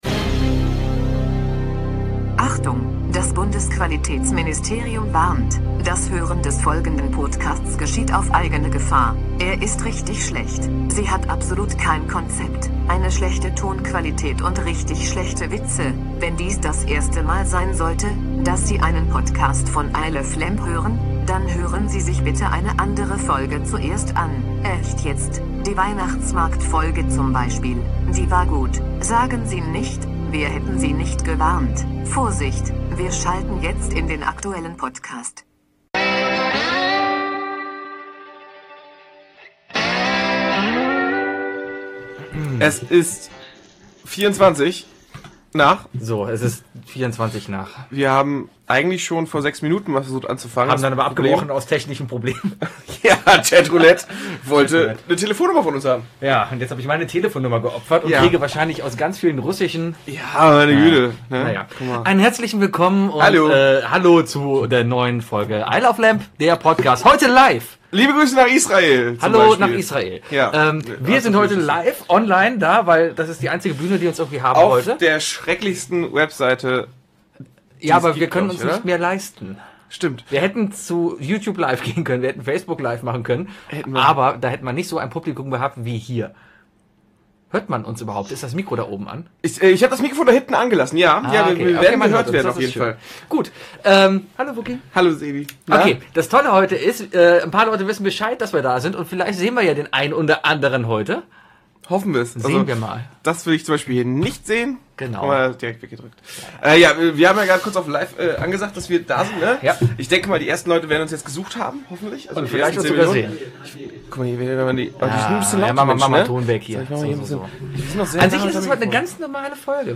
Deswegen hatte man die Wahl vernünftig zu sein, keine Folge raus zu bringen und dafür nächste Woche wieder mit der gewohnten inhaltlichen Kompetenz und Qualität aufzutreten, oder aber an Karneval betrunken in einem Hausflur mit dem iPhone aufgenommen irgendwas labern und eine neue Folge aufnehmen.